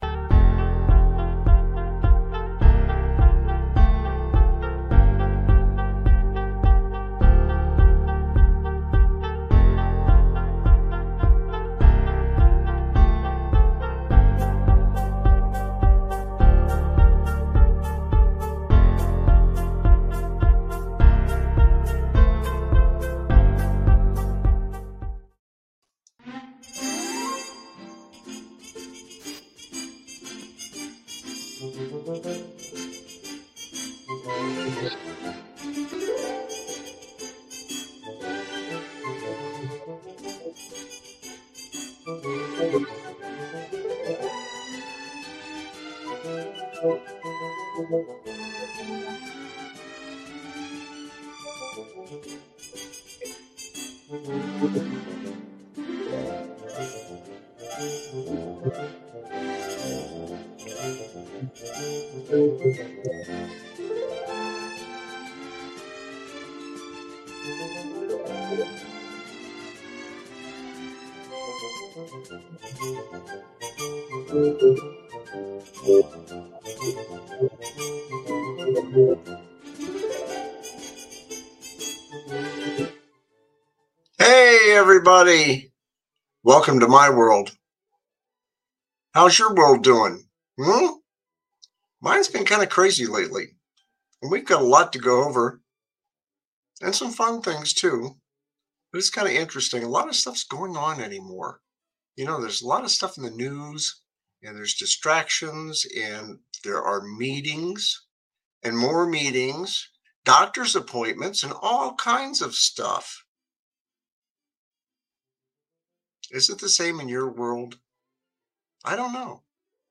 My WorldLive, Laff, Whatever is a satirical talk show that tackles the absurdities of life with ahealthy dose of humor.
No topic is off-limits, and his guests, arotating cast of comedians, commentators, and everyday folks, add their own uniqueperspectives to the mix.